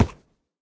sounds / mob / horse / wood6.ogg
wood6.ogg